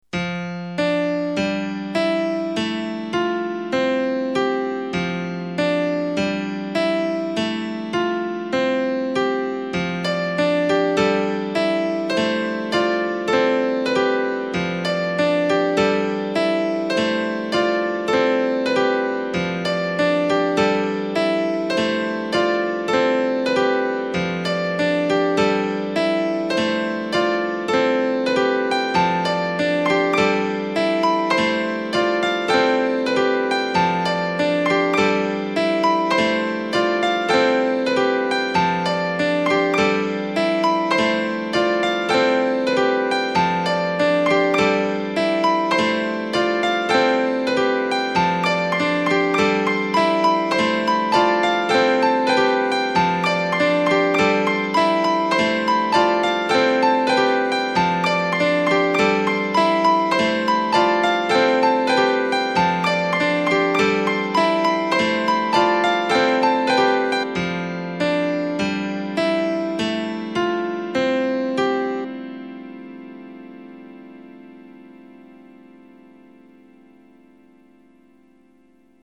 01:19 Classical 1.7 MB